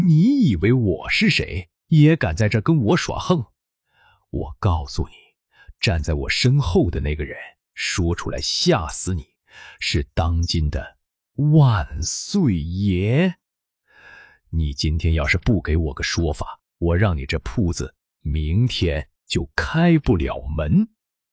其新音色的提示词为“用尖锐刻薄的嗓音，带着狐假虎威的得意感说话，在提到大人物的身份时故意放慢语速并加重语气，营造压迫感。”
音频中，音色与新闻播报的声线保持一致，在说“万岁爷”、“开不了门”等重点内容时，还可以拉长声线、加重语气。